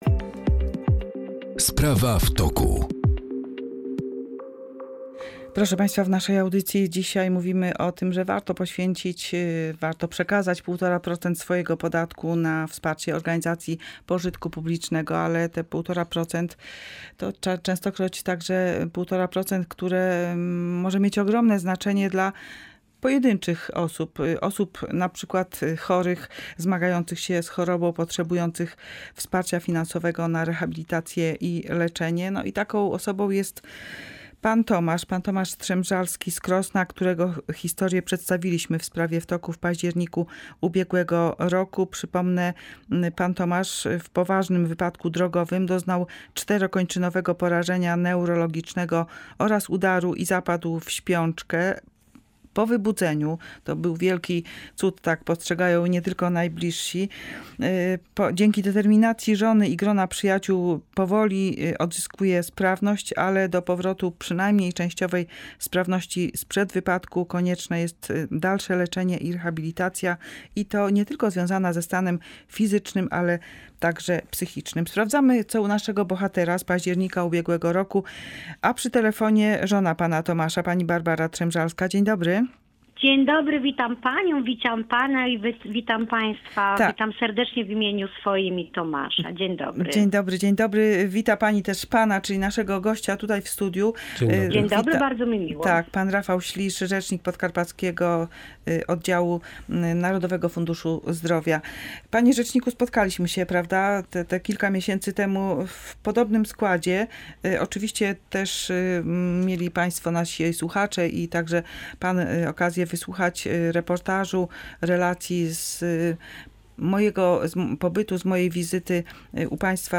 W programie rozmowa